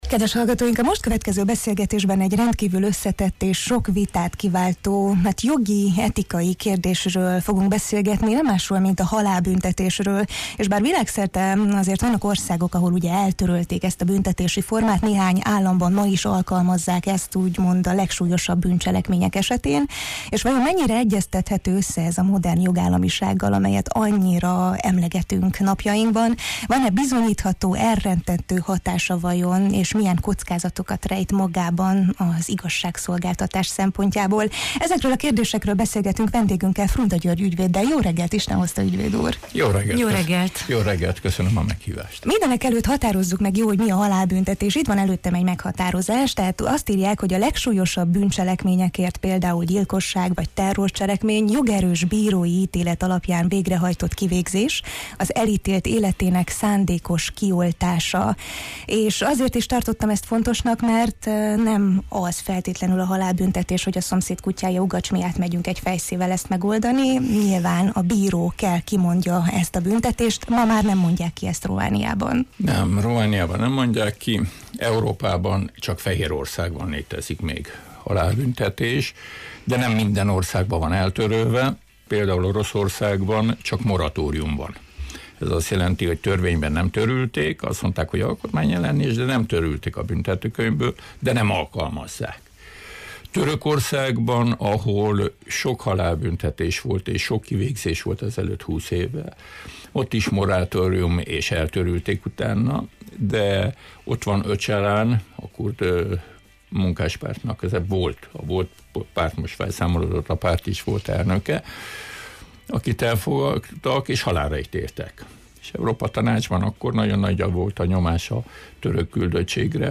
Ezekre a kérdésekre kerestük a választ Frunda György ügyvéddel, aki tapasztalataival és szakmai érveivel segít árnyaltabban látni ezt az érzékeny és megosztó kérdéskört: